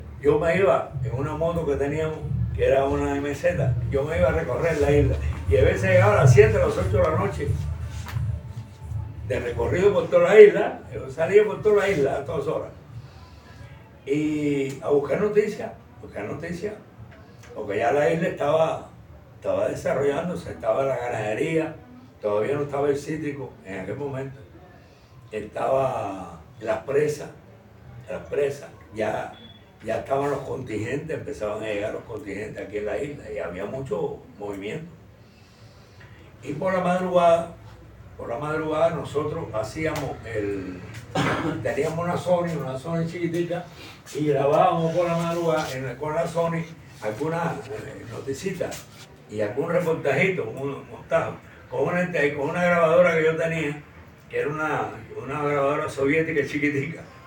El encuentro no sumó años, más bien sapiencia e historia contenida en la memoria y el alma de jubilados de diferentes medios de comunicación, quienes fueron convocados por la Unión de Periodistas de Cuba (Upec) en la Isla de la Juventud, en ocasión de la Jornada de la Prensa Cubana.